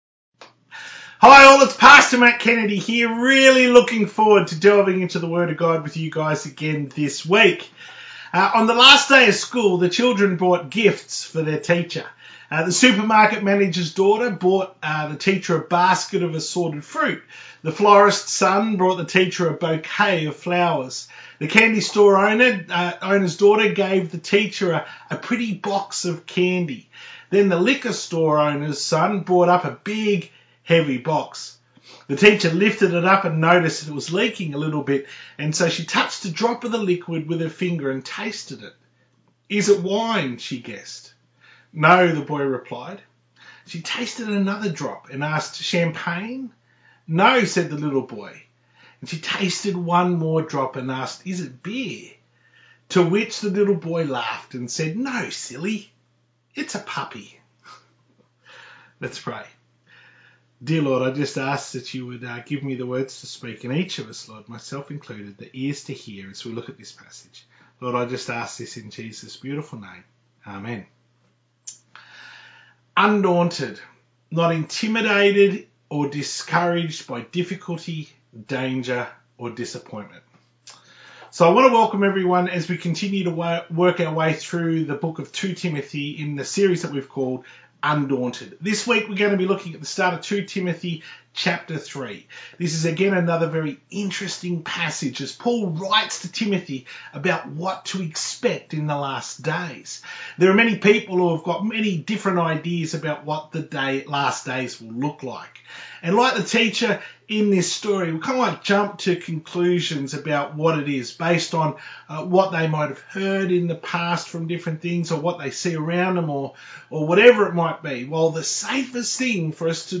To view the Full Service from 27th December 2020 on YouTube, click here.